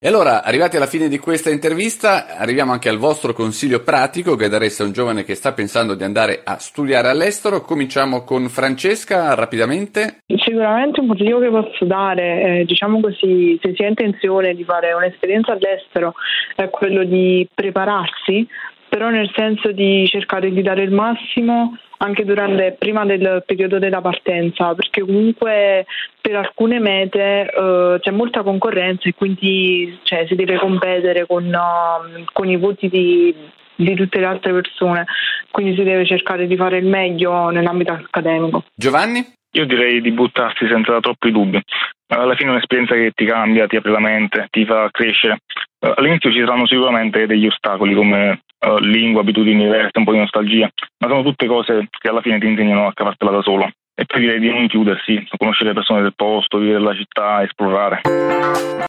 Finale-Erasmus-Corea-Sud.mp3